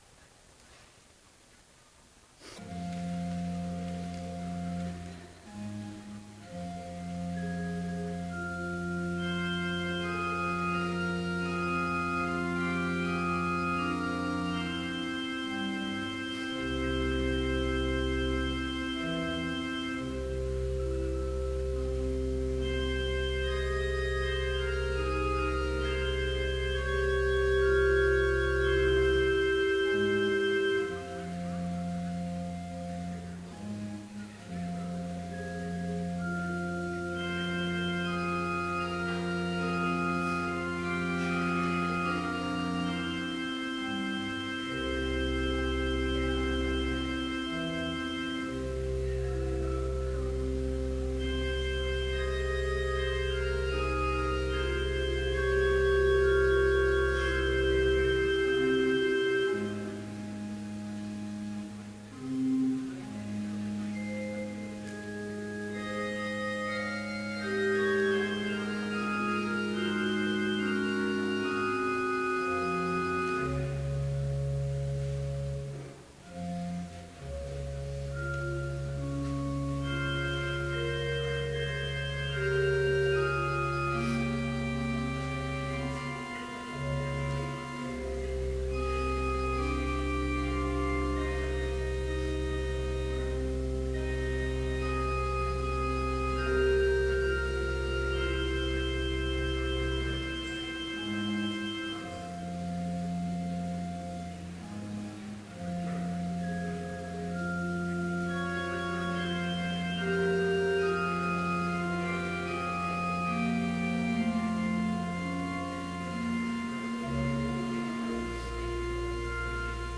Complete service audio for Chapel - March 3, 2015